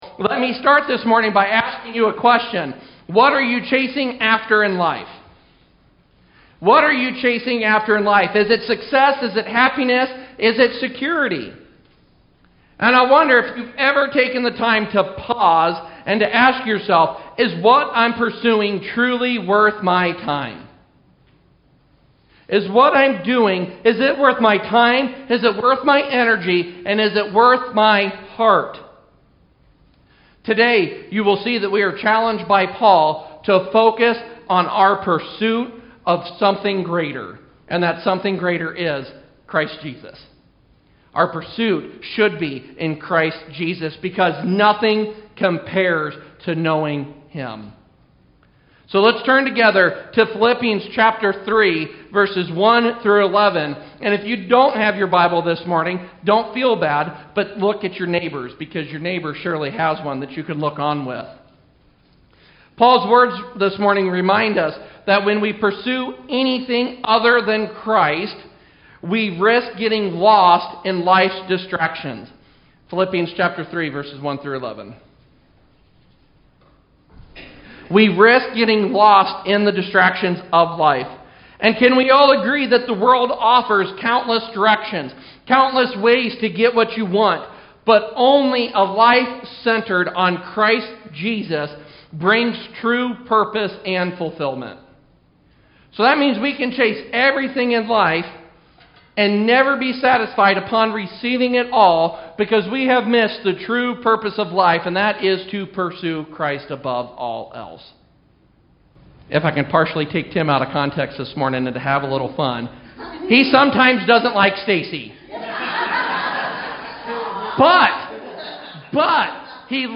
Sermons | Engage Church